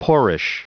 Prononciation du mot poorish en anglais (fichier audio)
Prononciation du mot : poorish